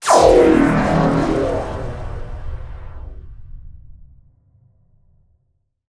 SPACE_WARP_Complex_06_stereo.wav